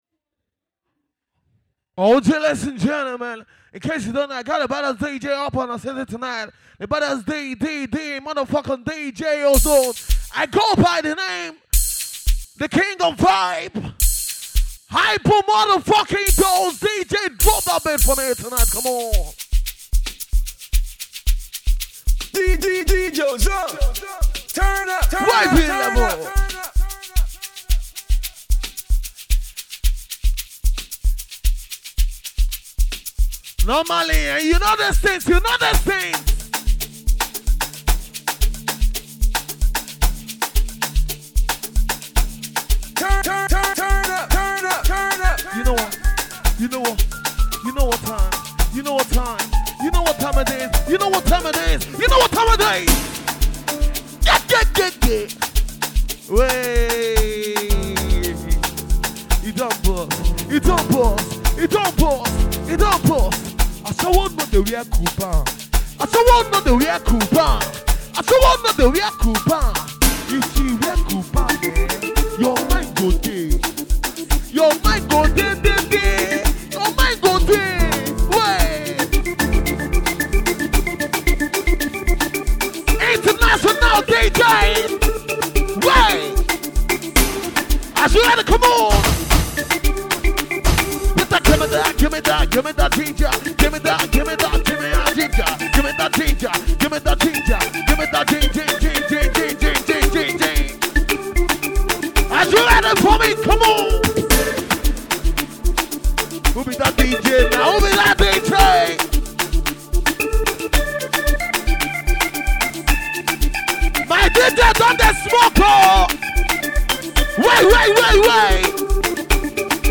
amapiano songs
mixed together with good vibes